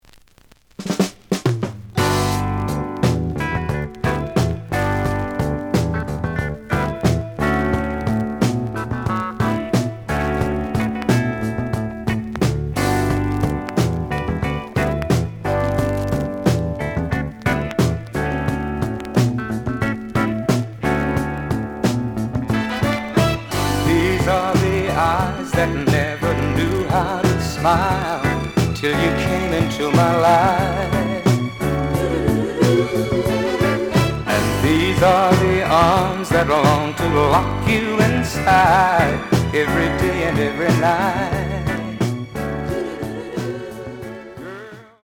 The audio sample is recorded from the actual item.
●Genre: Soul, 70's Soul
Slight noise on A side.